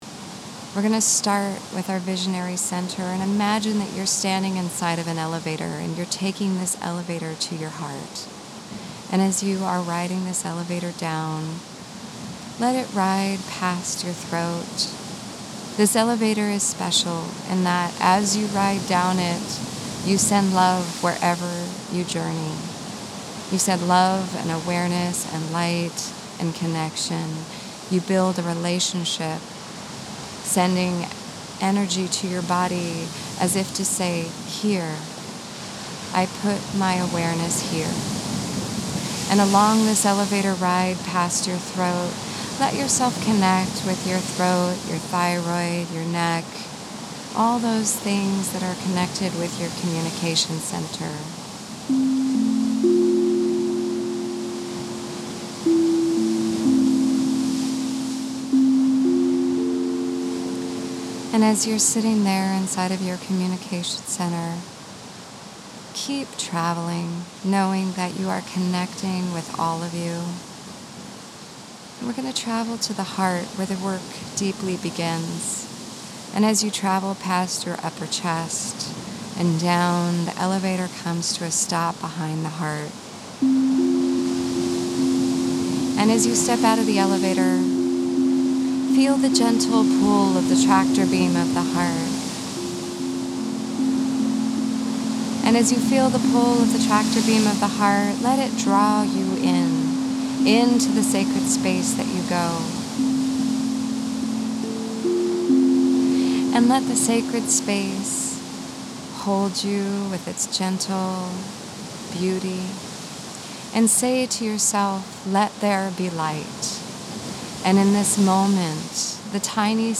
Guided Meditation: Heartlight